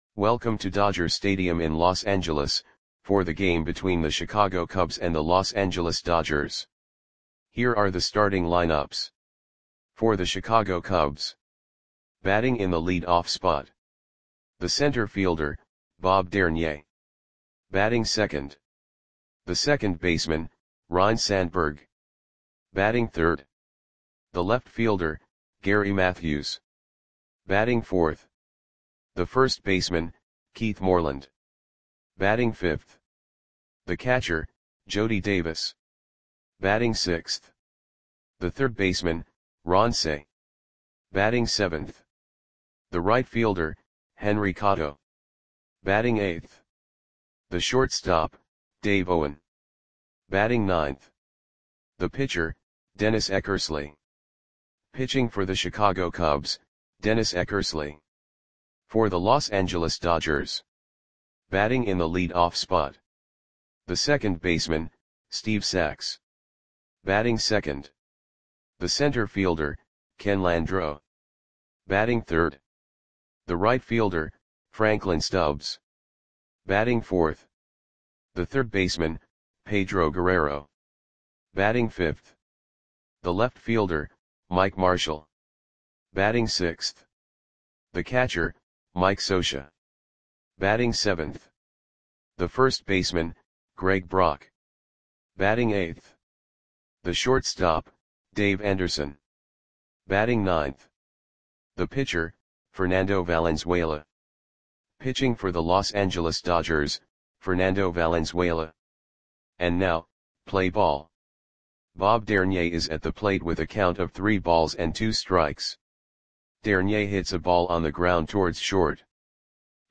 Audio Play-by-Play for Los Angeles Dodgers on July 1, 1984
Click the button below to listen to the audio play-by-play.